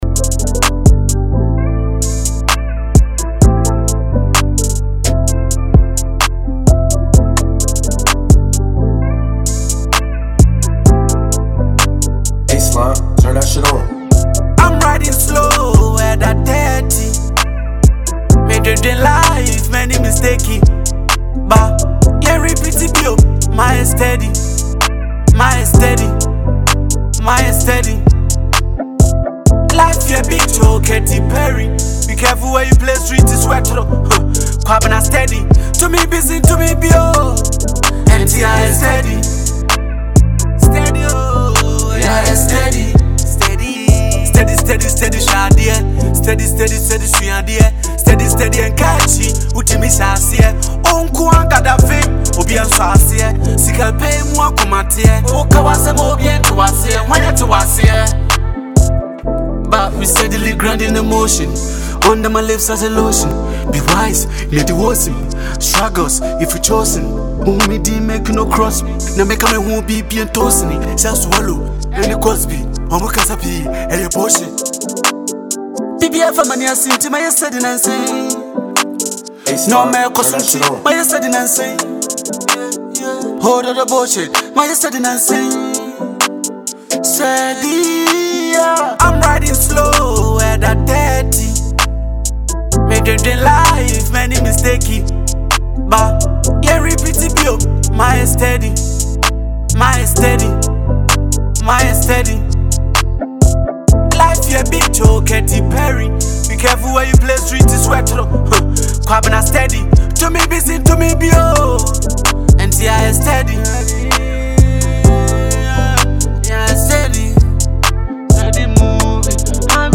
a smooth and motivational Ghanaian hip-hop record
calm, reflective verses
Genre: Hip-Hop / Conscious Rap